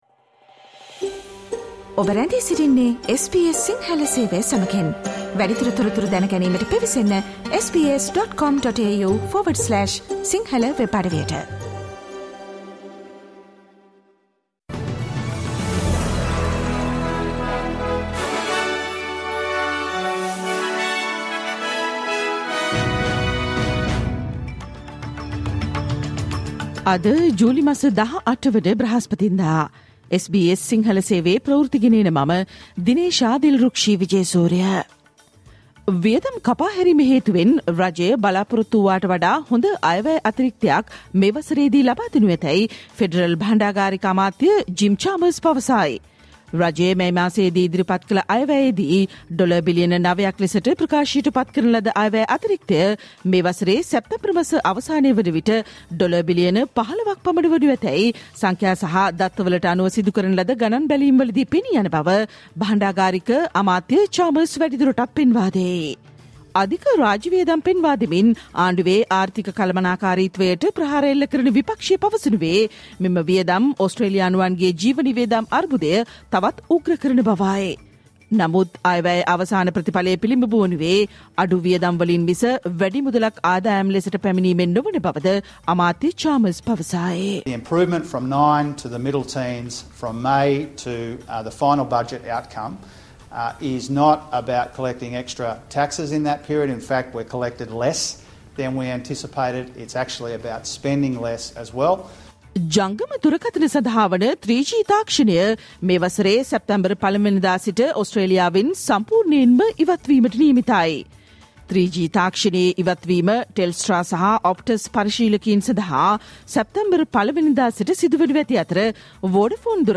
Australia news in Sinhala, foreign and sports news in brief.